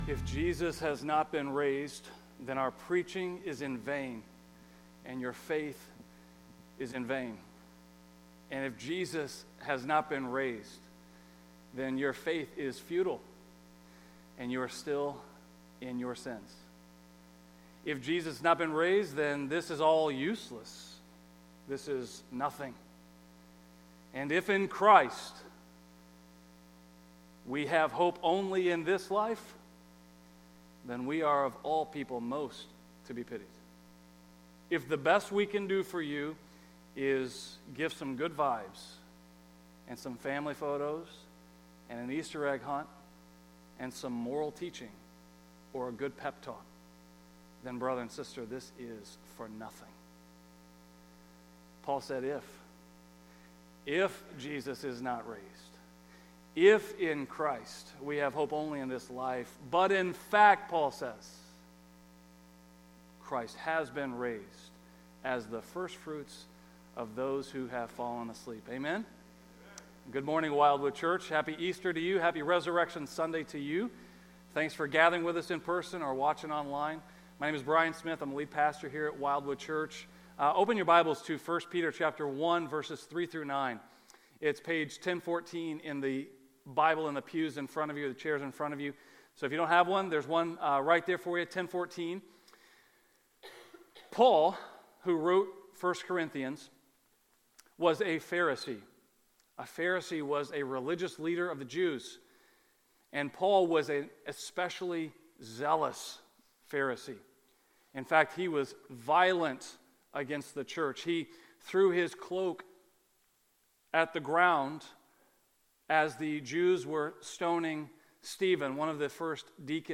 A message from the series "Resurrection Hope."